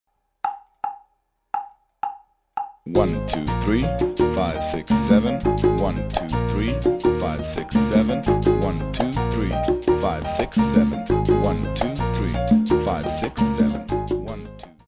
Par exemple, sur les partitions suivantes, les jeux de la cloche, des congas et de la basse.
timingsalsa.mp3